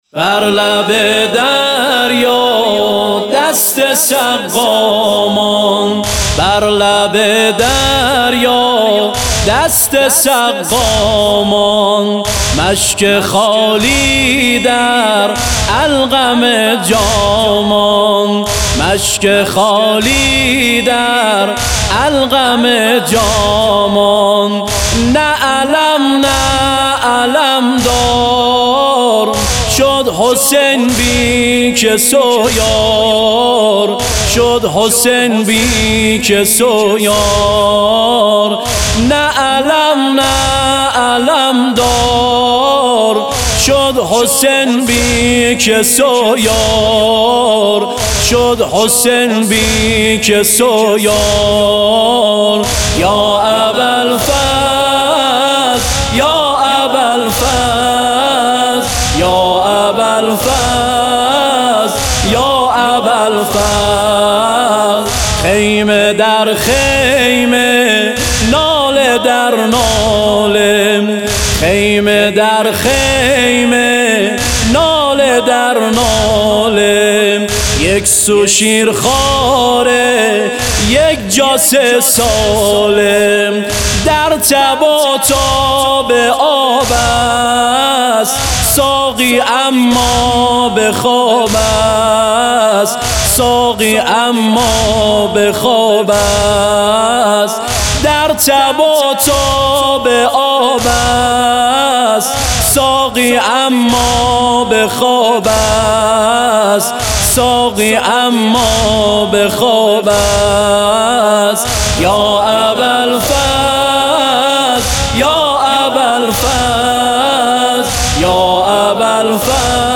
نوحه زنجیر زنی بر لب دریا دست سقا ماند